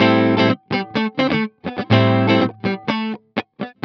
16 GuitarFunky Loop A.wav